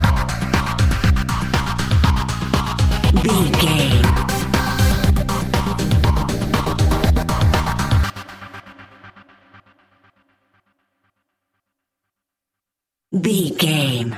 Aeolian/Minor
drum machine
synthesiser
electric piano
Eurodance